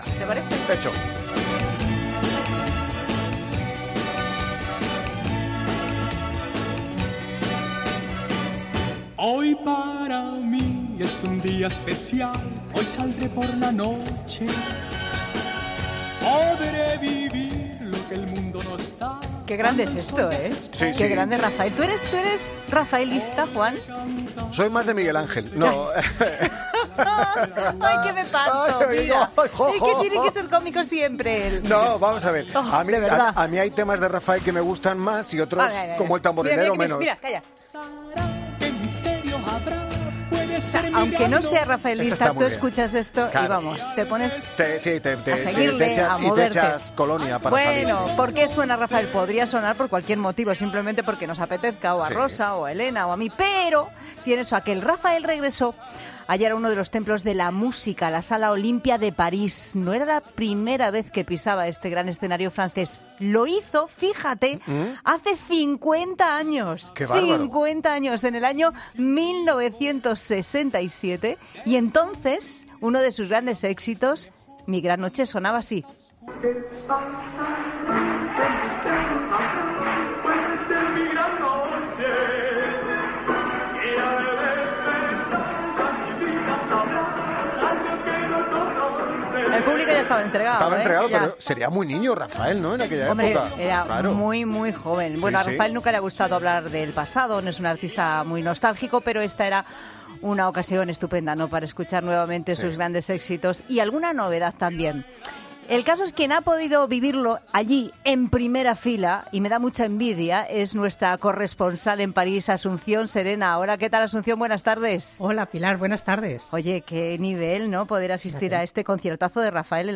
El cantante atendió a COPE antes de su concierto en París de este domingo.
Raphael atiende a COPE antes de su concierto en París